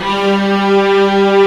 FLSTRINGS1G3.wav